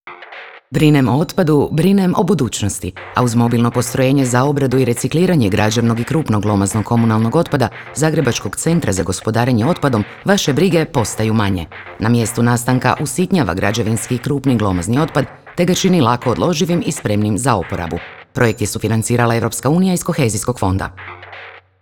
MOBILNO POSTROJENJE v4_wav radio spot.wav